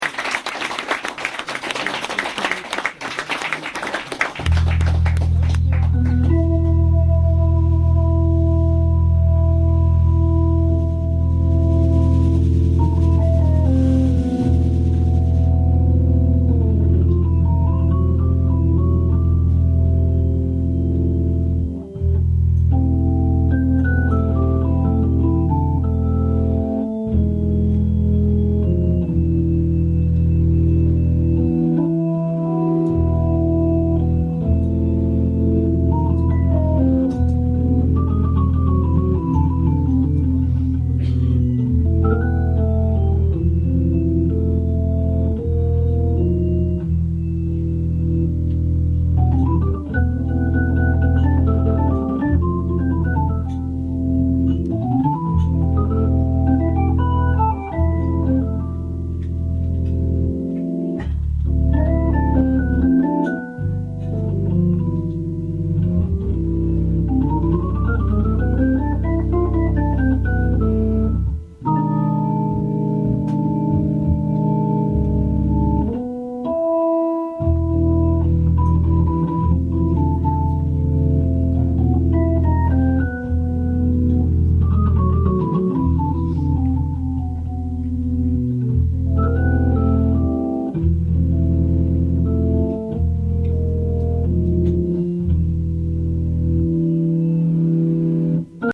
l'intro seulement....en live